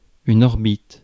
Ääntäminen
IPA: [ɔʁ.bit]